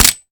weap_mike9a3_fire_last_plr_mech_01.ogg